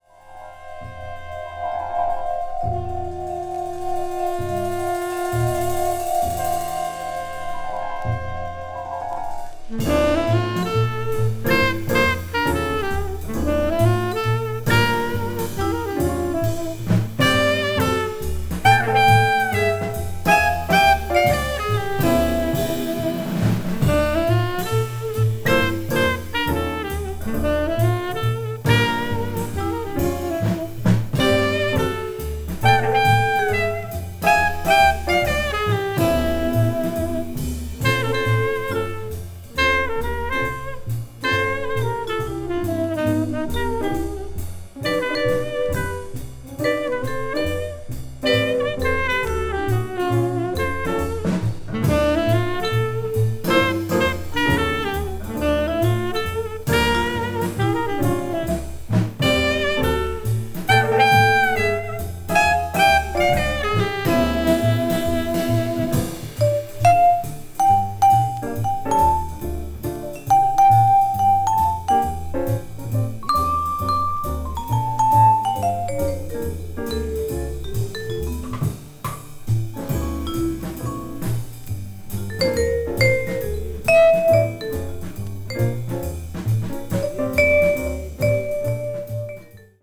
media : EX-/EX-(薄いスリキズによるわずかなチリノイズ/一部軽いチリノイズが入る箇所あり)
bop   modern jazz   mood jazz